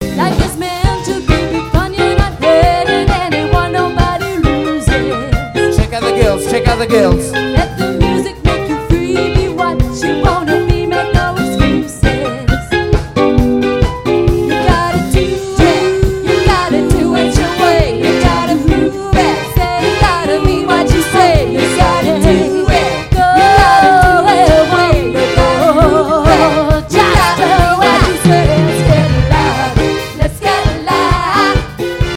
PLAYING LIVE!!!!